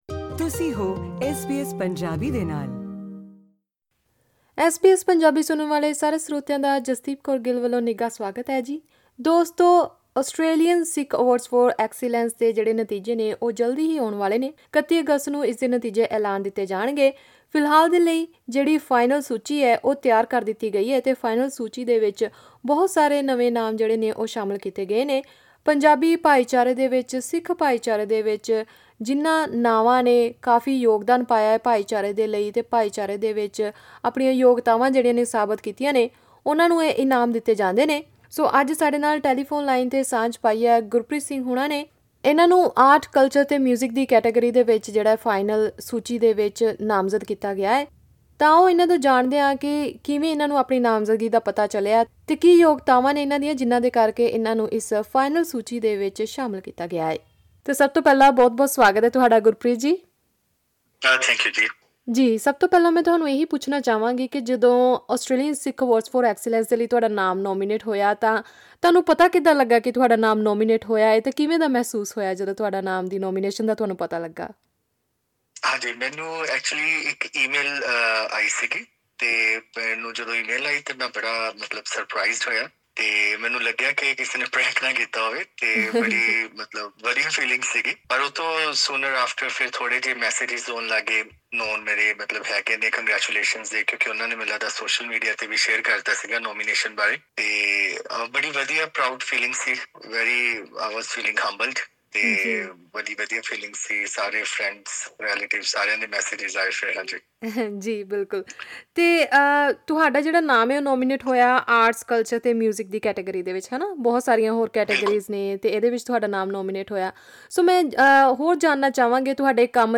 ਕਿਵੇਂ ਇੱਕ ਸਾਇੰਸ ਦਾ ਵਿਦਿਆਰਥੀ ਸੰਗੀਤ ਦੀ ਦੁਨੀਆ ‘ਚ ਆਪਣਾ ਨਾਂ ਬਣਾ ਕੇ ਇੱਕ ਮਿਸਾਲ ਕਾਇਮ ਕਰ ਰਿਹਾ ਹੈ ਇਹ ਜਾਨਣ ਲਈ ਸੁਣੋ ਇਹ ਇੰਟਰਵਿਊ…